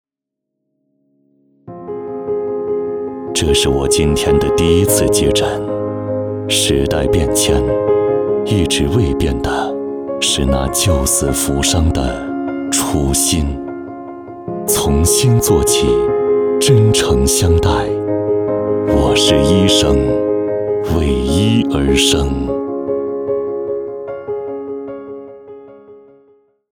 旁白-男27-沉稳-我是医生.mp3